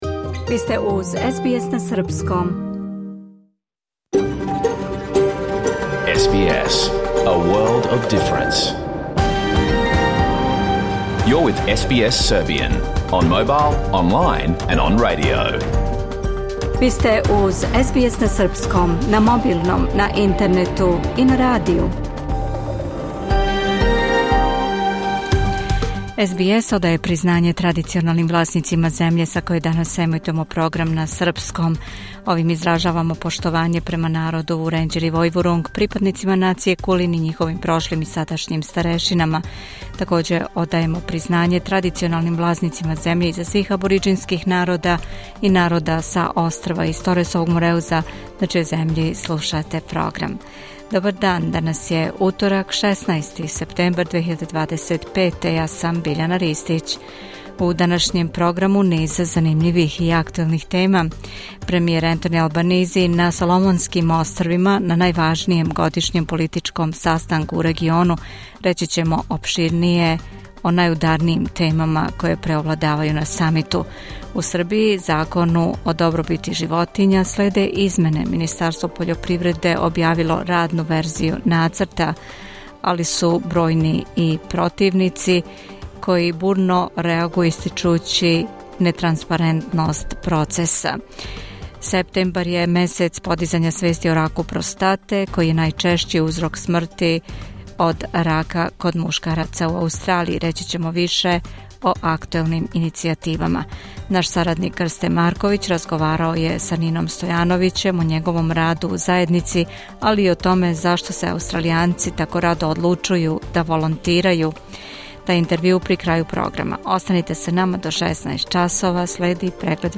Програм емитован уживо 16. септембра 2025. године
Тај интервју при крају програма.